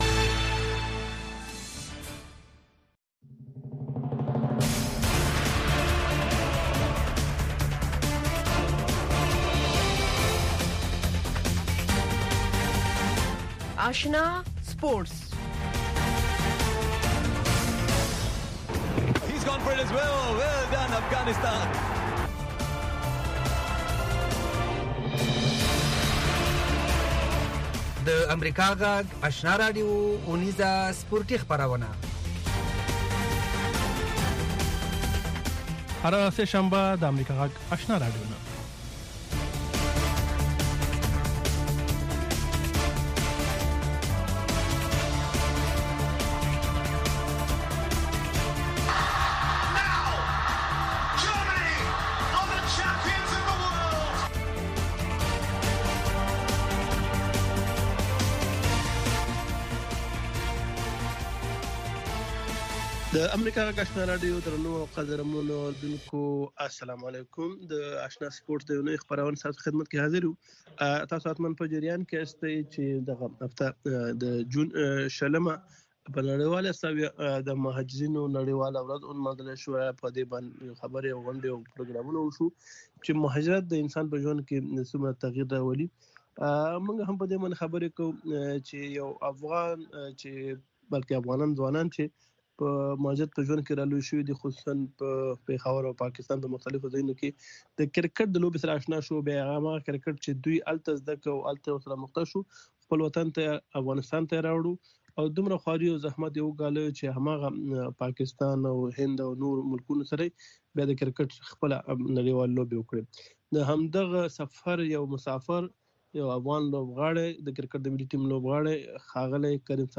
په دغې خپرونه کې د روانو چارو پر مهمو مسایلو باندې له اوریدونکو او میلمنو سره خبرې کیږي.